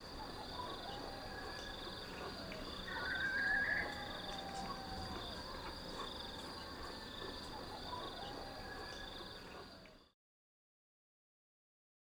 Cape Verde Barn Owl
A single rising, modulated perennial screech.
1-25-Cape-Verde-Barn-Owl-Perennial-screech.wav